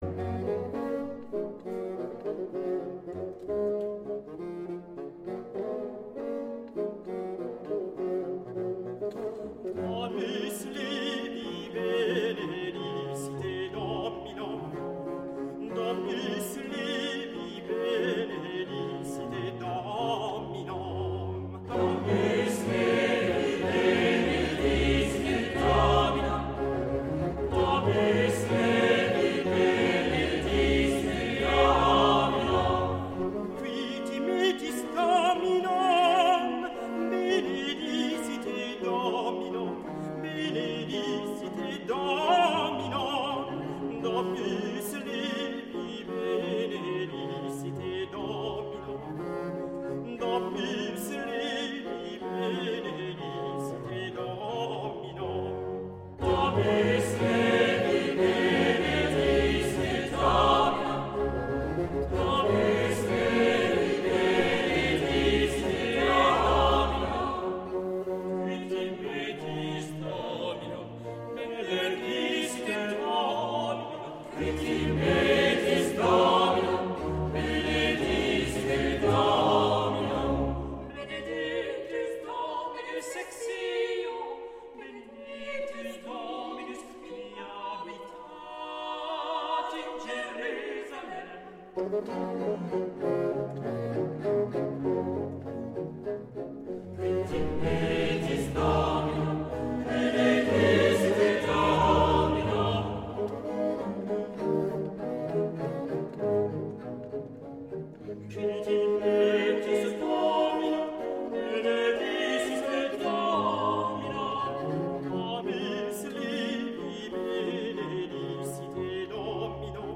Grand motet
Prelude - Recit de Taille - Choeur, avec Recits de ...